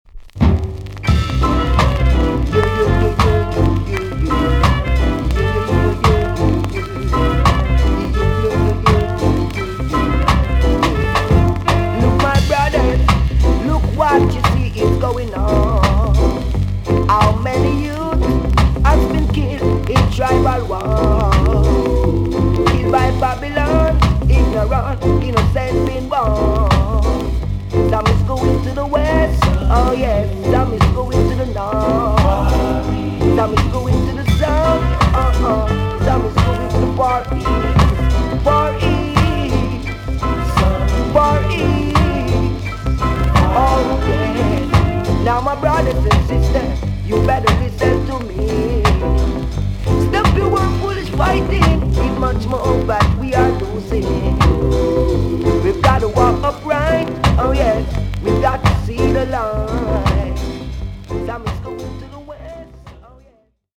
TOP >SKA & ROCKSTEADY
VG ok 全体的にチリノイズが入ります。